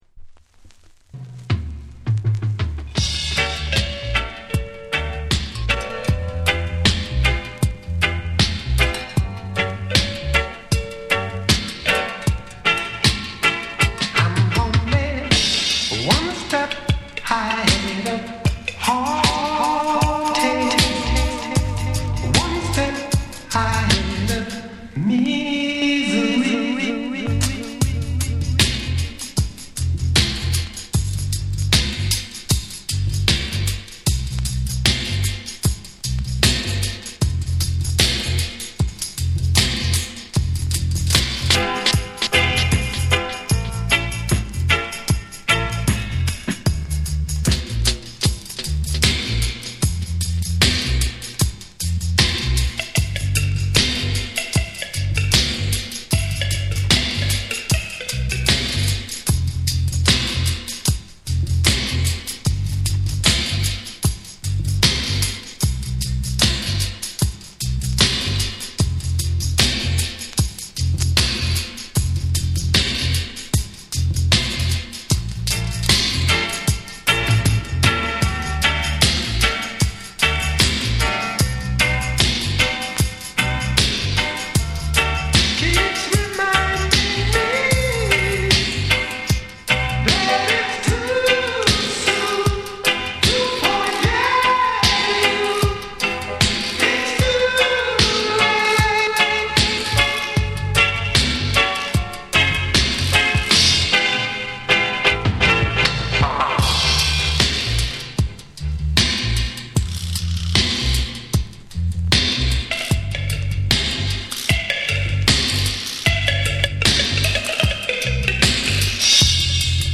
温かみのあるコーラスワークの余韻を残しつつ、エコーやリバーブが深く空間に広がる王道ルーツ・ダブを展開。
じっくりと浸れるクラシックなダブ作品。
REGGAE & DUB